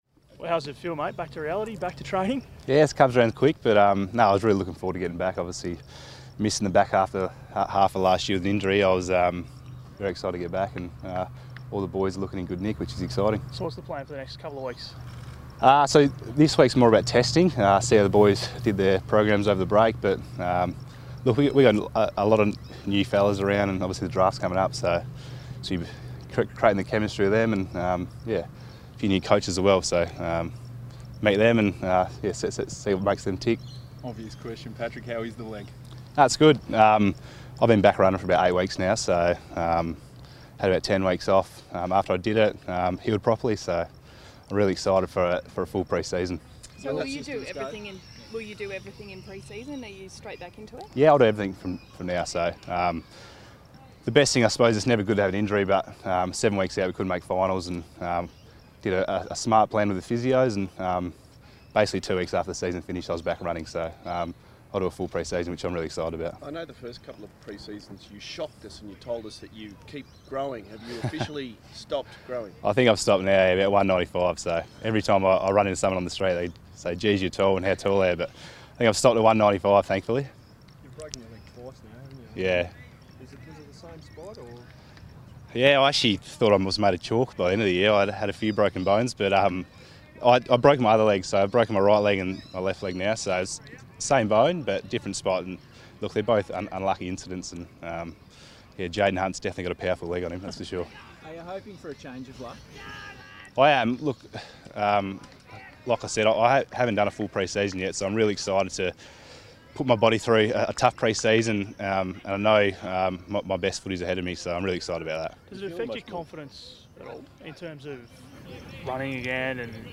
Patrick Cripps press conference | November 15
Carlton midfielder Patrick Cripps speaks to the media at the beginning of the 2018 pre-season.